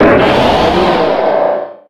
Audio / SE / Cries / SCEPTILE_1.ogg